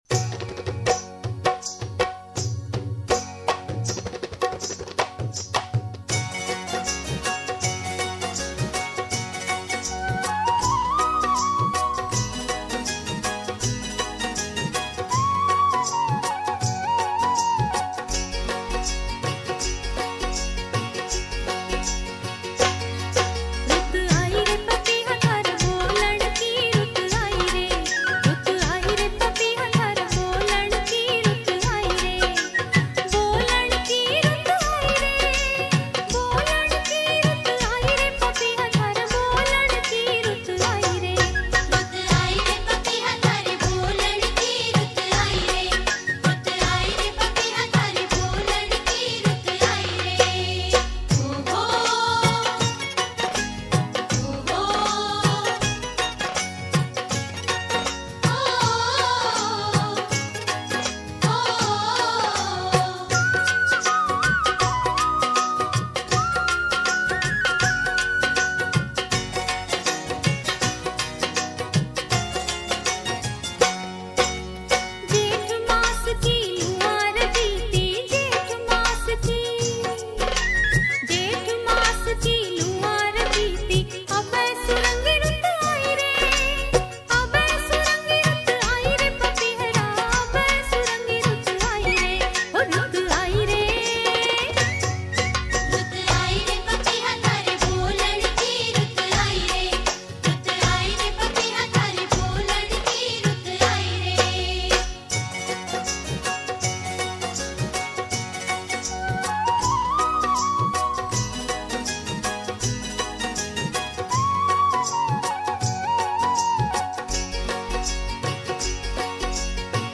[Folk]